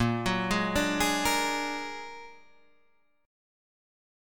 Bb+7 chord